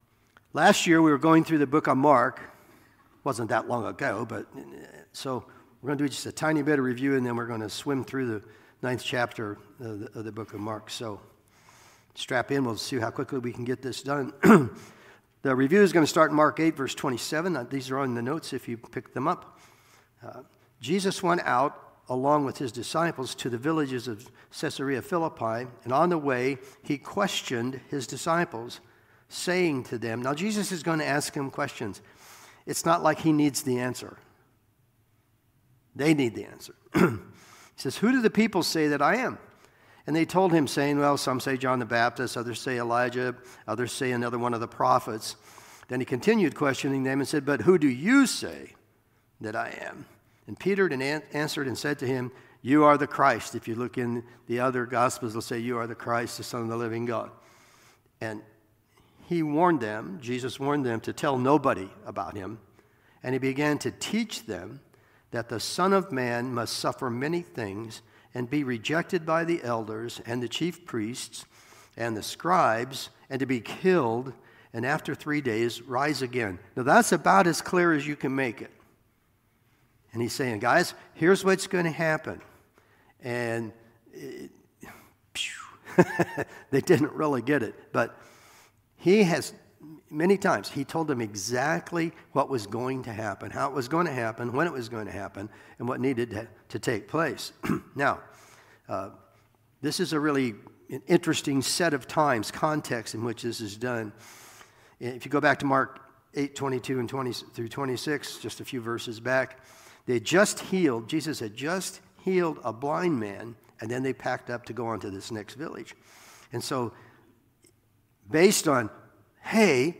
Sunday Morning Sermon Download Files Notes